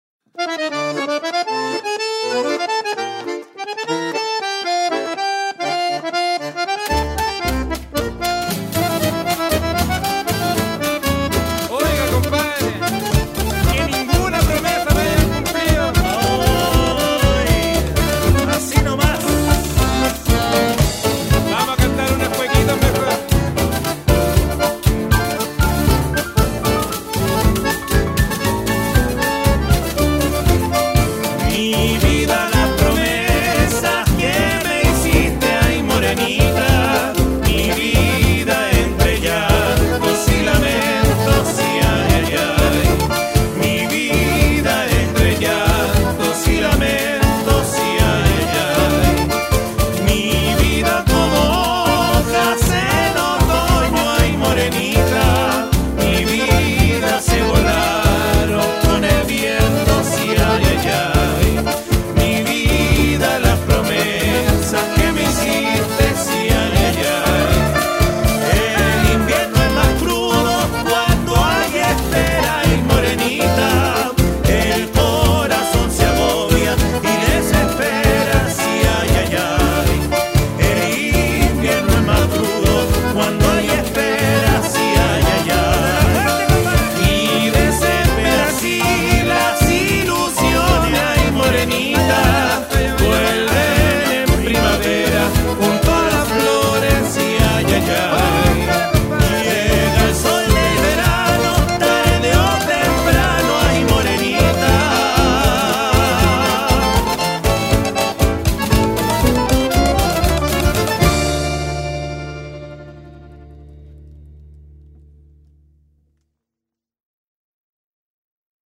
cueca